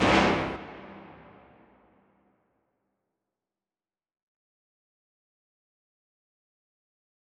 MDMV3 - Hit 13.wav